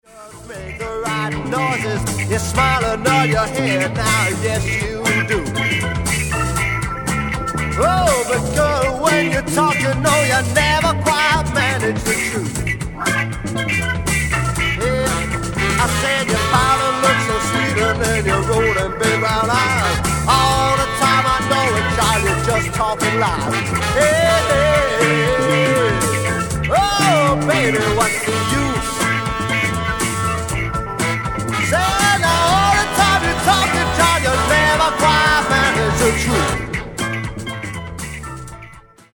BLUES ROCK / COUNTRY BLUES / SSW